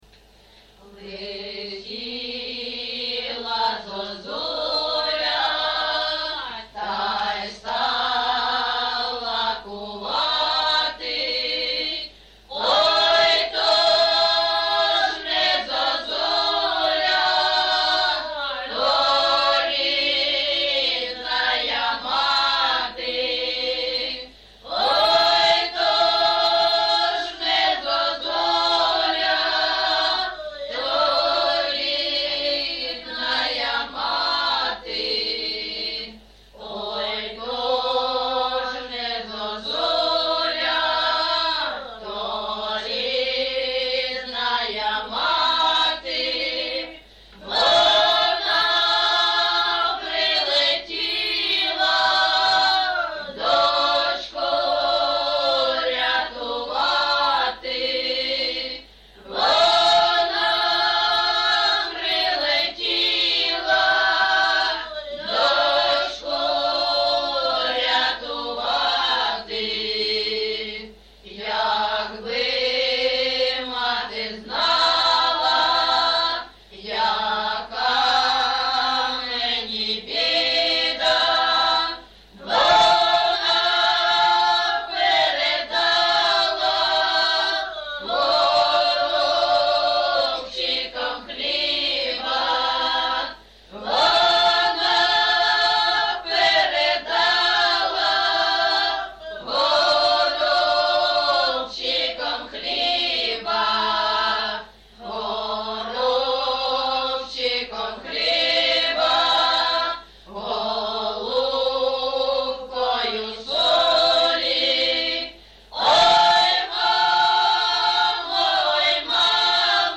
ЖанрПісні з особистого та родинного життя
Місце записус-ще Щербинівка, Бахмутський район, Донецька обл., Україна, Слобожанщина